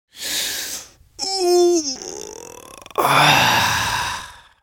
جلوه های صوتی
دانلود صدای خمیازه از ساعد نیوز با لینک مستقیم و کیفیت بالا